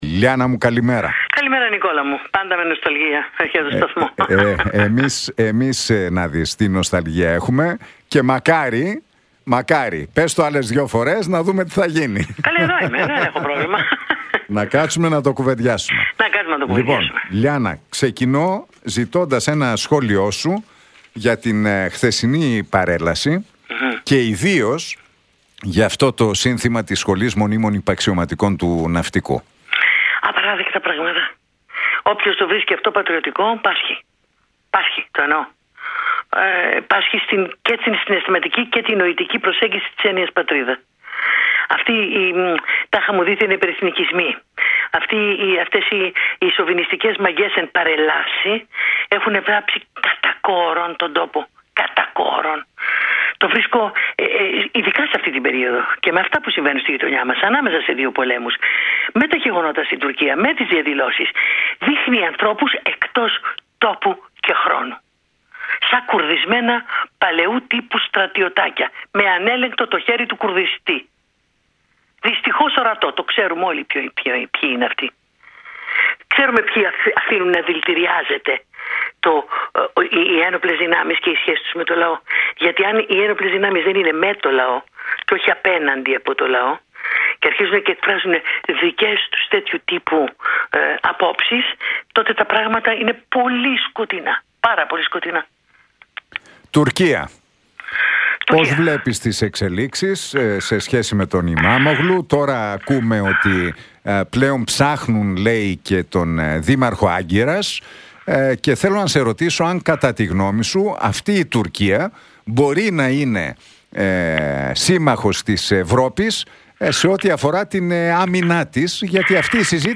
Για τα υβριστικά συνθήματα που ακούστηκαν στην παρέλαση για την 25η Μαρτίου κατά της Τουρκίας, για τις εξελίξεις στη γείτονα χώρα αλλά και τον Τραμπ μίλησε η Λιάνα Κανέλλη στον Realfm 97,8 και την εκπομπή του Νίκου Χατζηνικολάου.